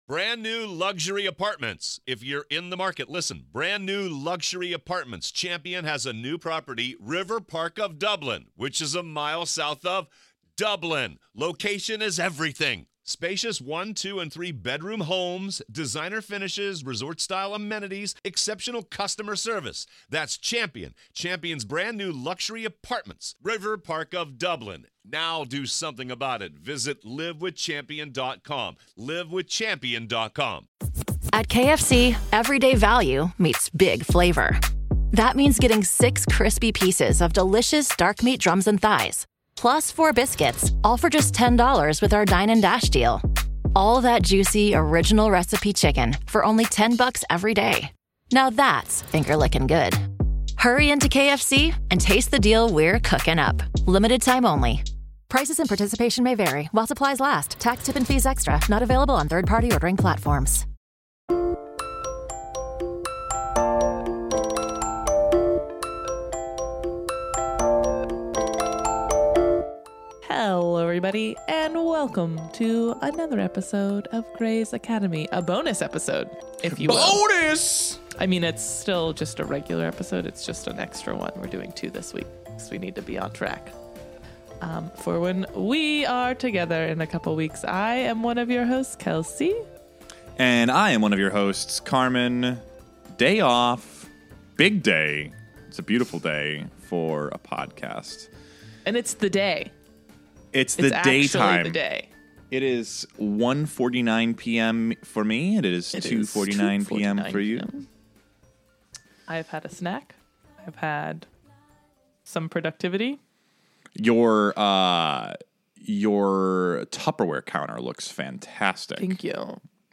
We have some technical difficulties in the first 5 minutes (barely noticeable) but it is fixed after that.